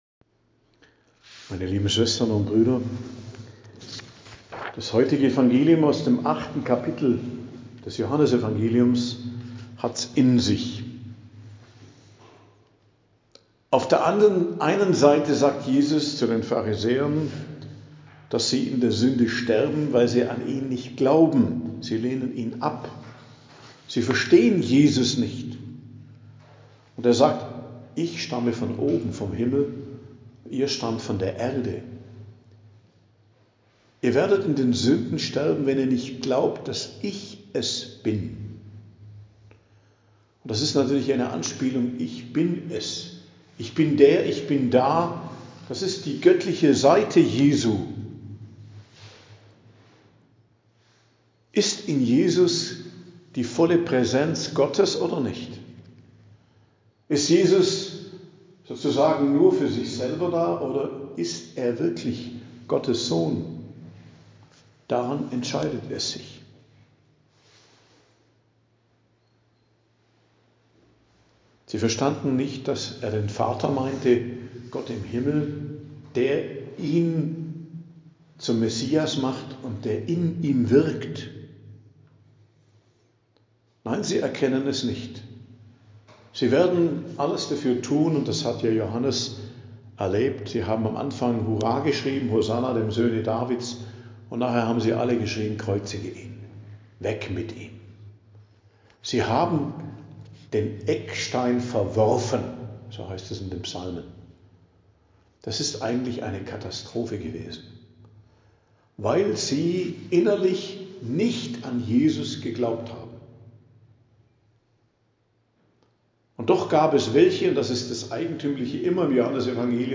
Predigt am Dienstag der 5.